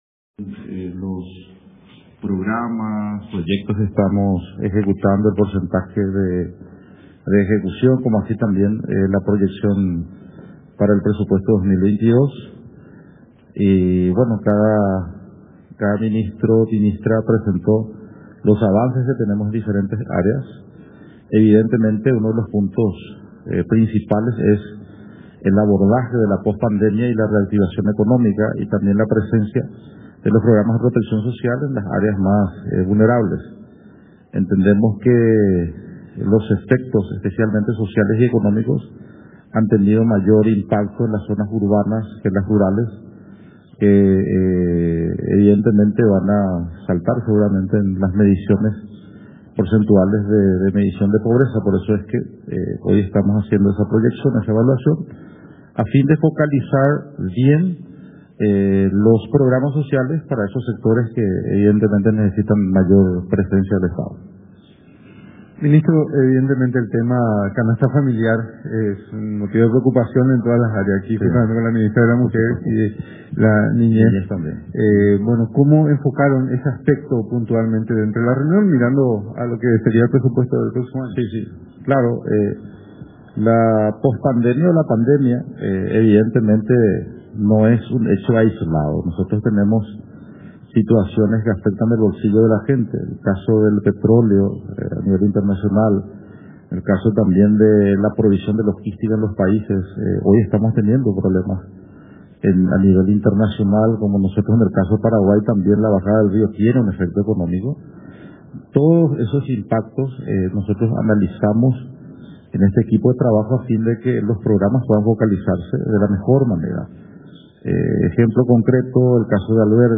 En conferencia de prensa, el ministro Varela comentó que el abordaje de la pospandemia y la recuperación económica, son los principales ejes en los que se aboca actualmente el Ejecutivo.
minsitromariovarela.wav